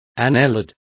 Complete with pronunciation of the translation of annelid.